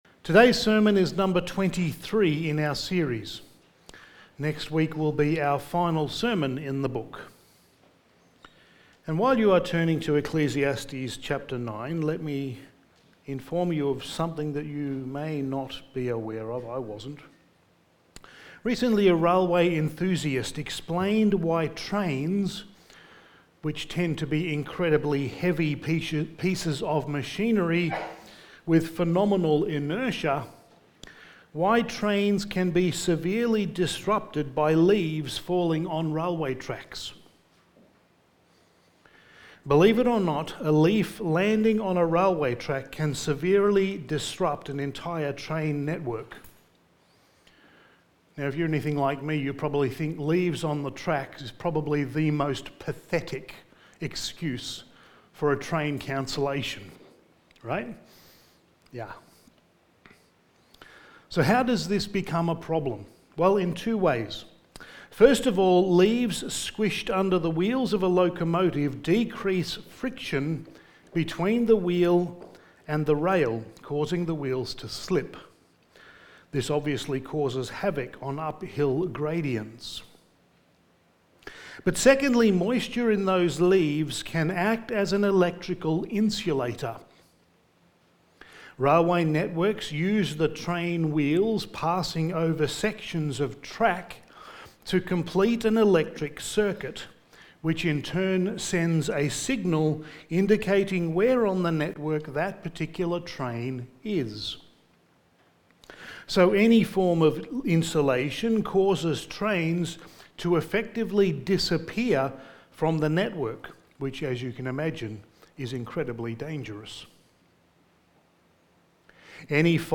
Studies in the Book of Ecclesiastes Sermon 23: Remember Your Creator in Your Youth
Service Type: Sunday Morning